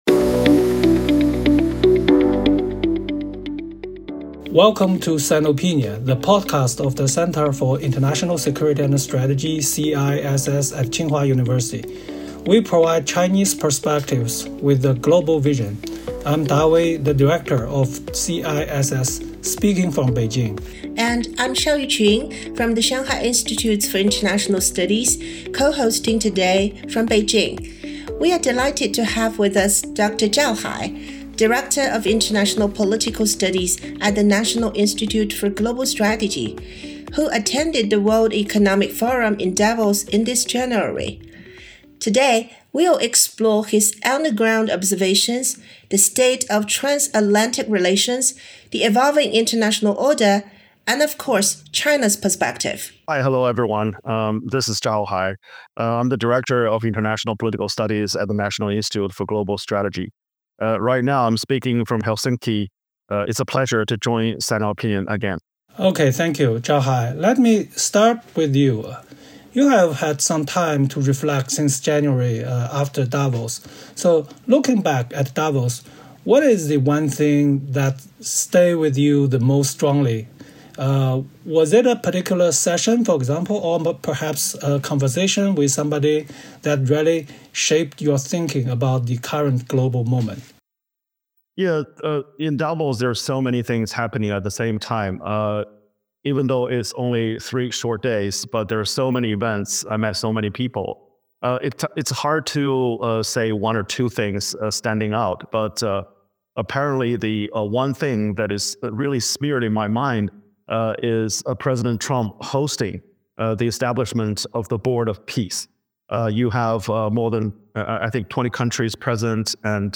三位学者结合达沃斯的现场观察，深入探讨当前国际秩序究竟是已经进入“新阶段”，还是仍处在旧秩序解体与新秩序生成之间的过渡期，以及这一进程对中国意味着什么。